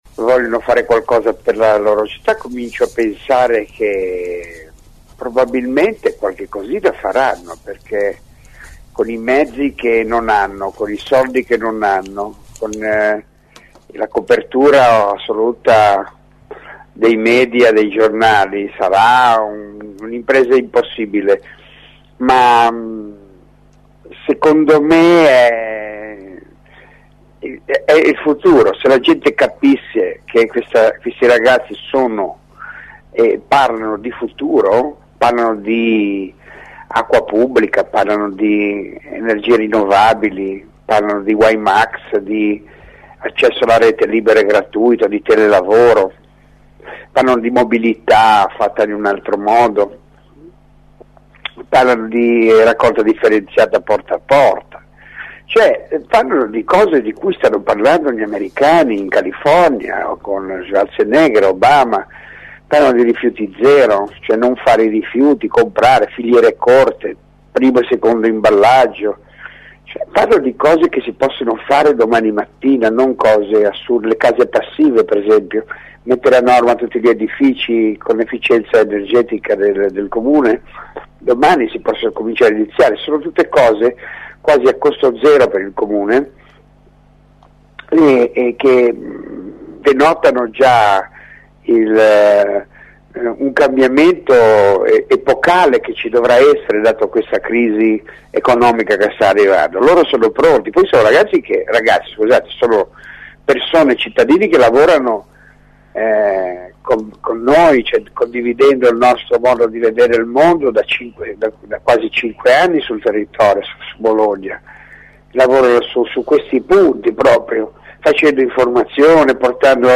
Prima di lui, Beppe Grillo è intervenuto in collegamento telefonico sempre all’interno di Angolo B.